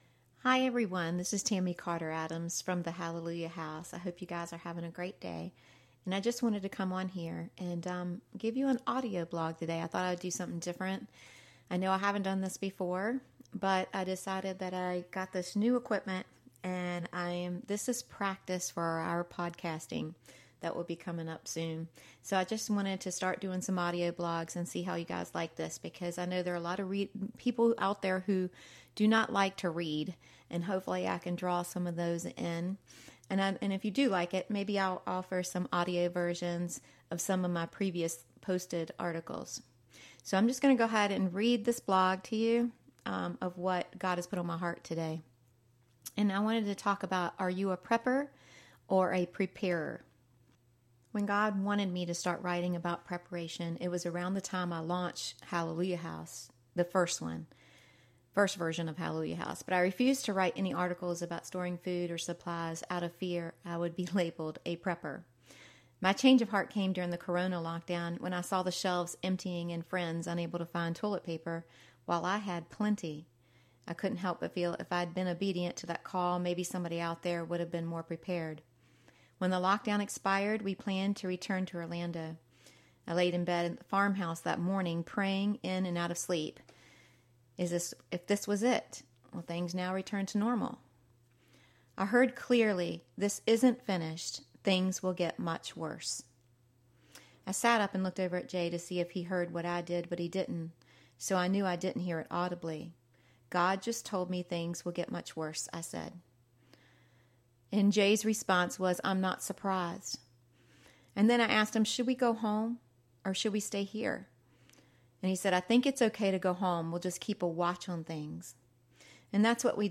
I’ve recently purchased audio equipment for our future podcasts, so I thought I’d try something new today.